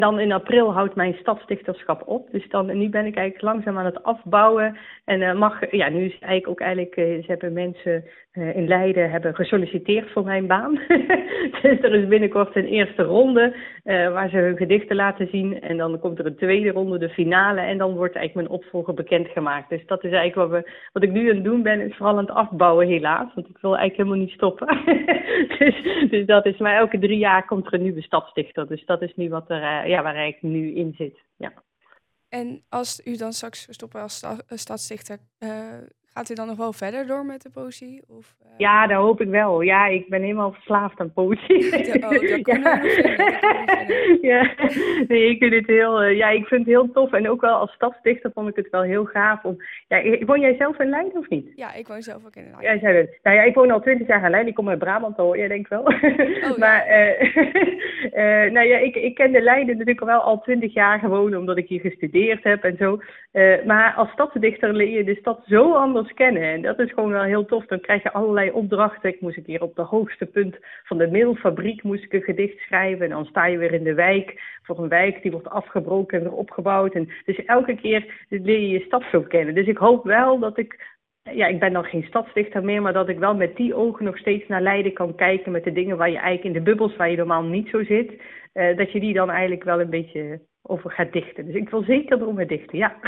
in gesprek met verslaggever